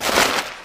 MISC Newspaper, Scrape 03.wav